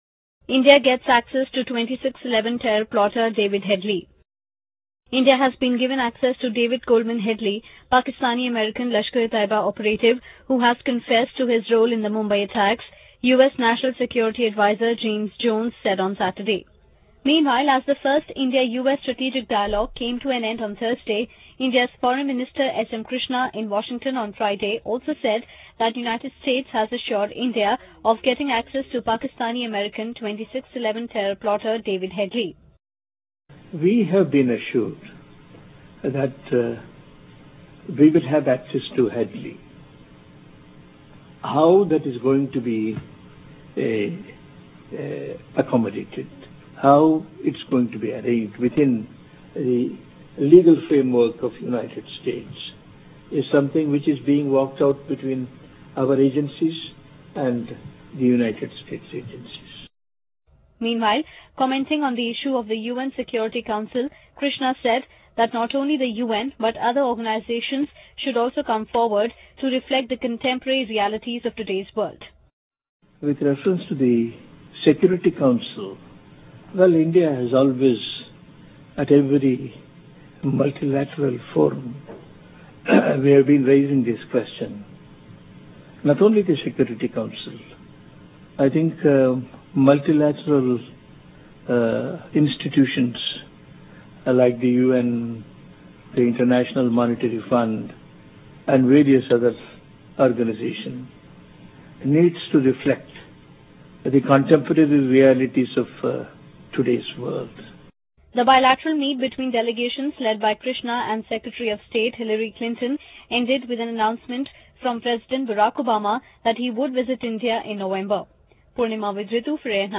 Addressing a meeting of the India-Sri Lanka Business Forum at Colombo on Friday Sri Lankan President Mahinda Rajapaksa called upon captains of Indian trade and industry to invest in the Emerald Island. President Rajapaksa said that the new areas and sectors have opened since the end of the conflict in his country, assuring local and foreign investors attractive and interesting opportunities.